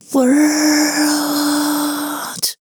WHISPER 10.wav